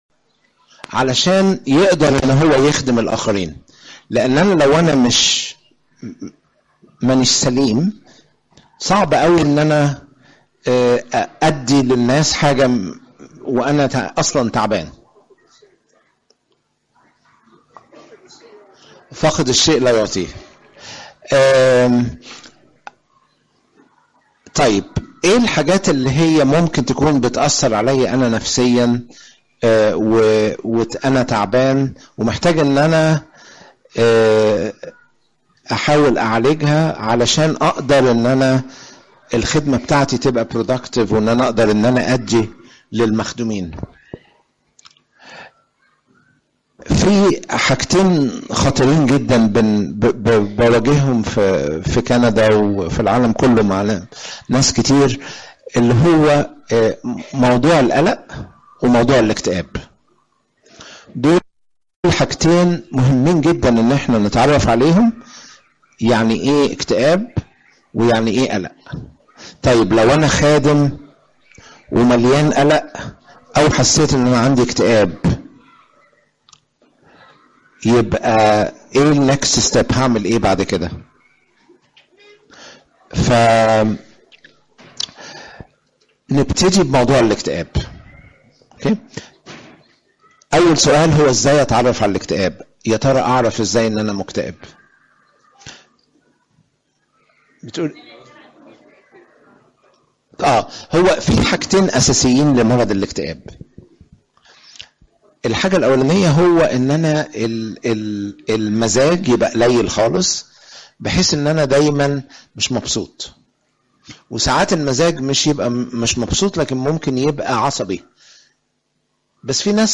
إجتماع الخدمة العام